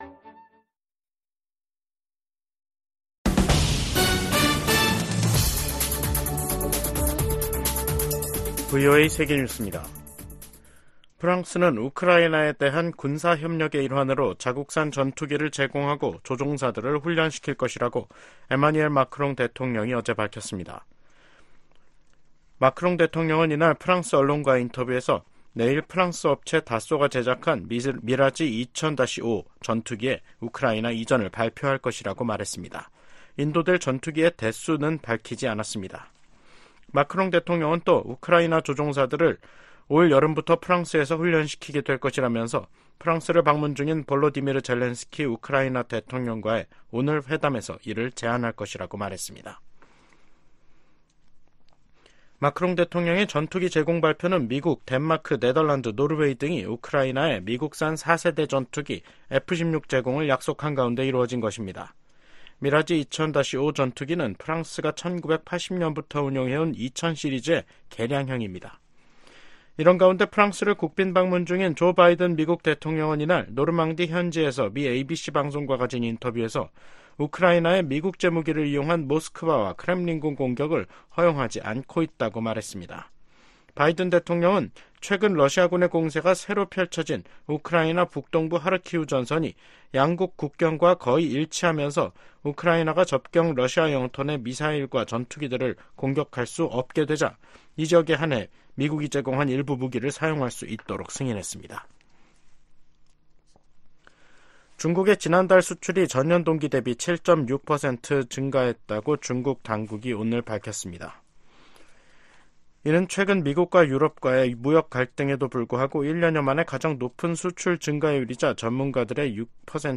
VOA 한국어 간판 뉴스 프로그램 '뉴스 투데이', 2024년 6월 7일 2부 방송입니다. 미국, 한국, 일본 3국의 협력은 인도태평양의 안보 구조와 정치 구조를 위한 ‘근본적인 체제’라고 미 국가안보부보좌관이 평가했습니다. 블라디미르 푸틴 러시아 대통령은 최근 세계 주요 뉴스통신사들과의 인터뷰에서 한국이 우크라이나에 무기를 공급하지 않고 있다고 이례적으로 감사 표시를 했습니다.